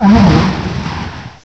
sovereignx/sound/direct_sound_samples/cries/tornadus_incarnate.aif at 5954d662a5762d73b073731aa1d46feab2481c5c